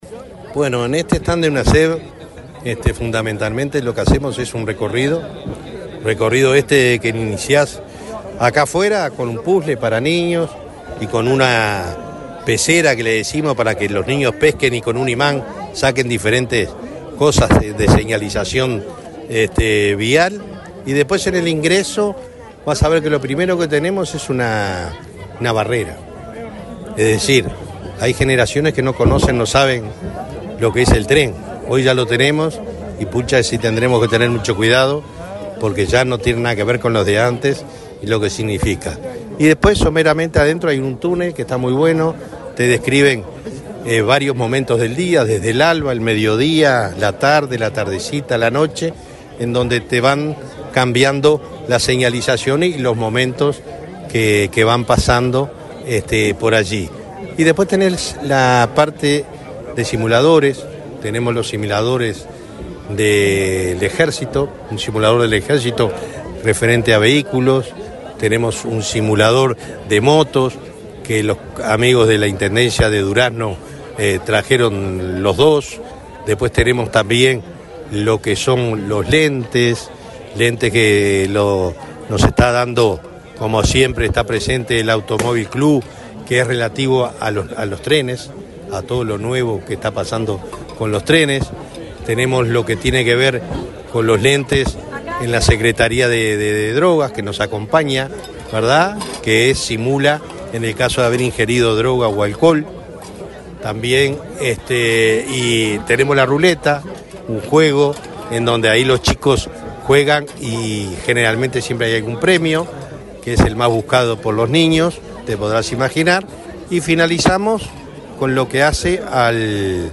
Entrevista al presidente de Unasev, Alejandro Draper
El presidente de la Unidad Nacional de Seguridad Vial (Unasev), Alejandro Draper, dialogó con Comunicación Presidencial, antes de inaugurar el stand